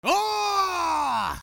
兵士(歓声)
ボイス